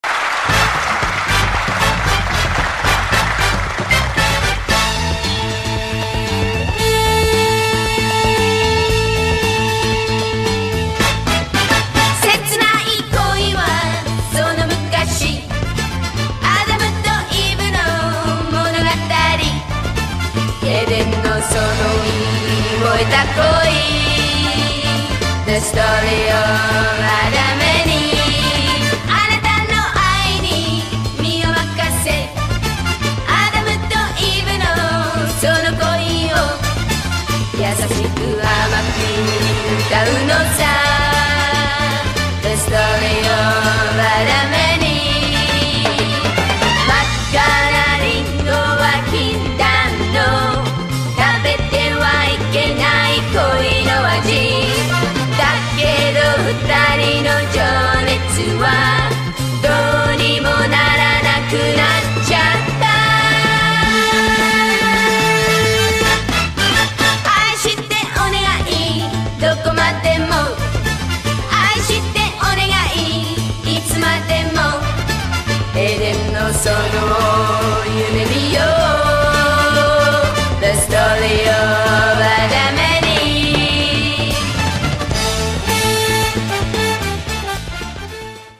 Помогите определить, чью песню перепевают японки?